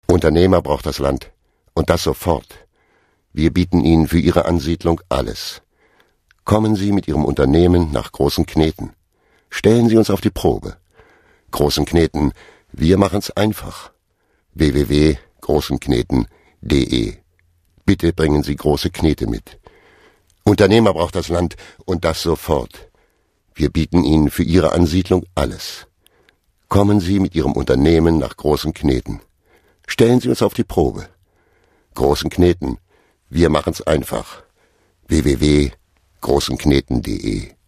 Agentur Sprecherdatei - Otto Sander Sprecher, Synchronsprecher, Stationvoice
Sprachproben von Otto Sander
Otto Sander spricht
Dank seiner warmen, kräftigen Stimme, die ihm den Beinamen The Voice einbrachte, wurde Otto Sander sehr häufig als Sprecher für Fernsehdokumentationen, Hörbücher und Hörspiele sowie als Synchronsprecher eingesetzt.